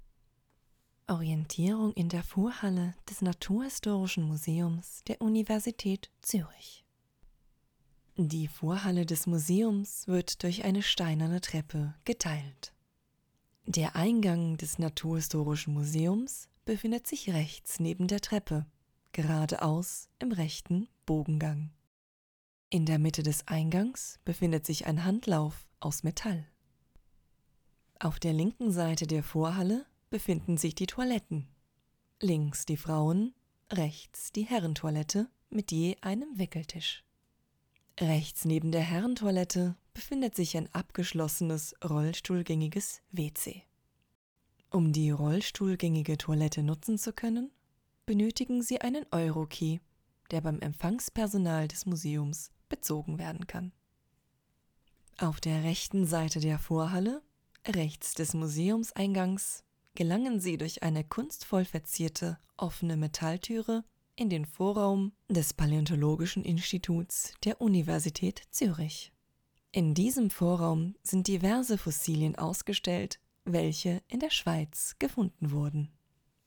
Audiodeskriptionen: